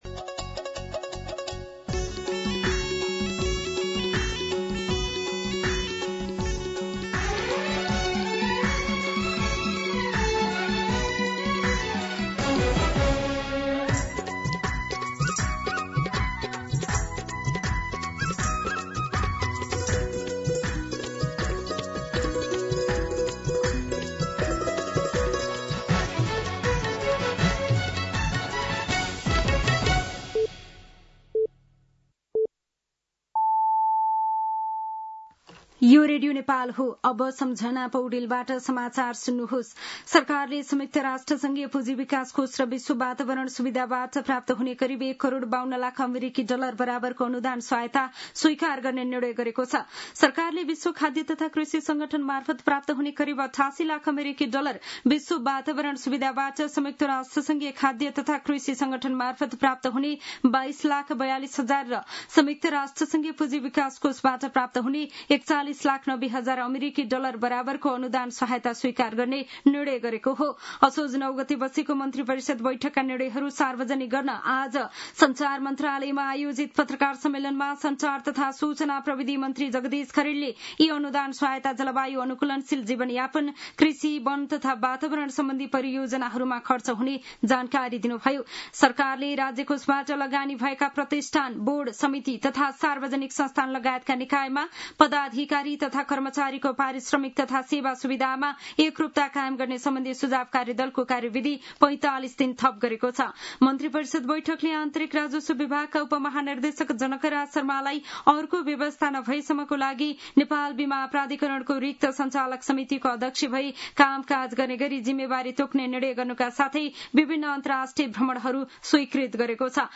दिउँसो ४ बजेको नेपाली समाचार : १० असोज , २०८२